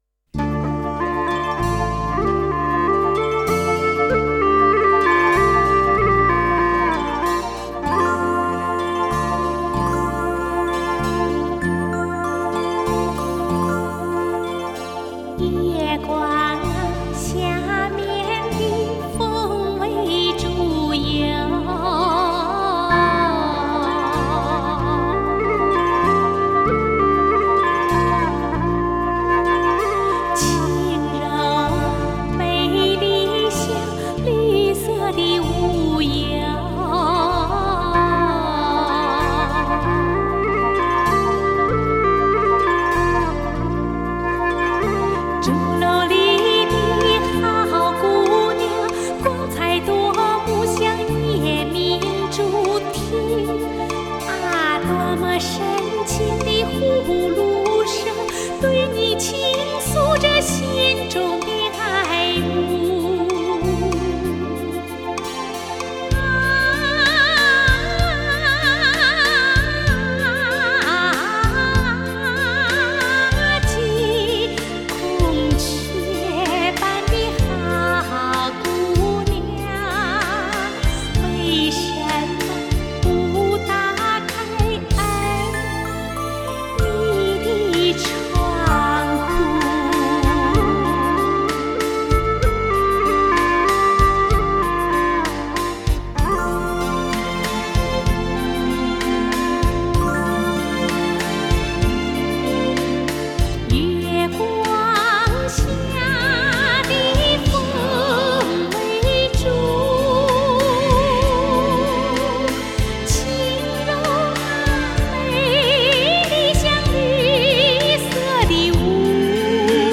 Жанр: Chinese pop ∕ Chinese folk